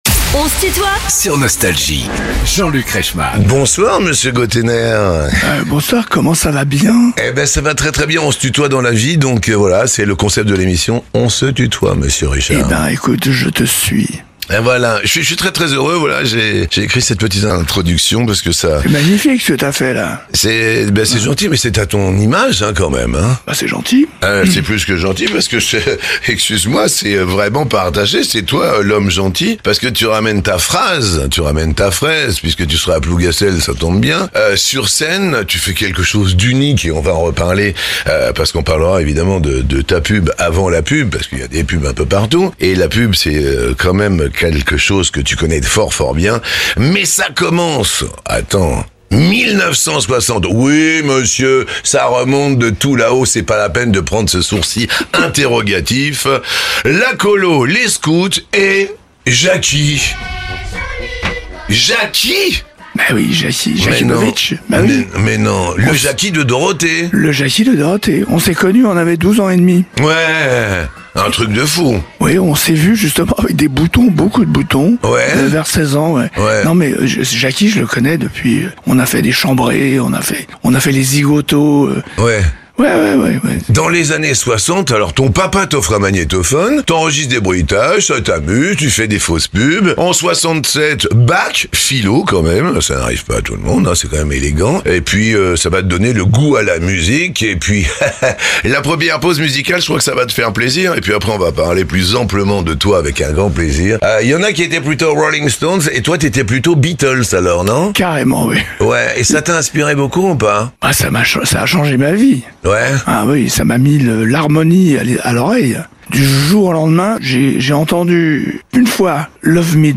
Richard Gotainer est l'invité de "On se tutoie ?..." avec Jean-Luc Reichmann